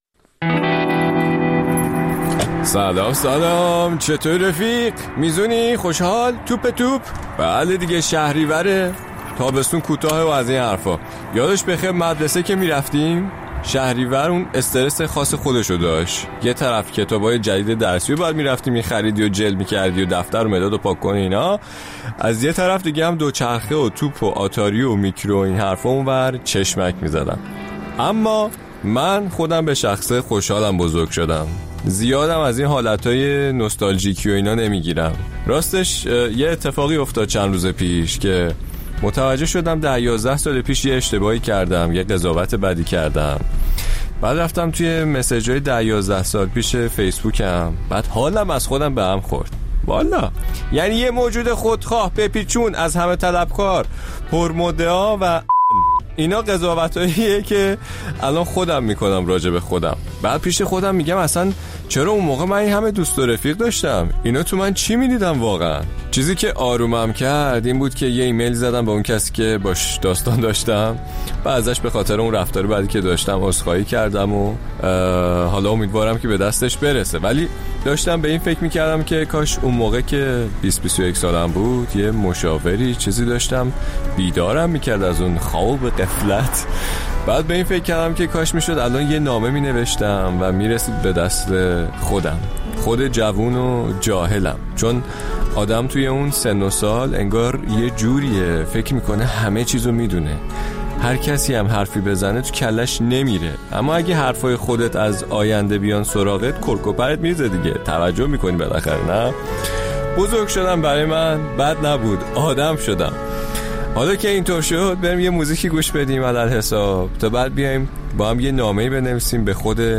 اپیزود شماره ۱۰۳ پادکست موسیقی جاده فرعی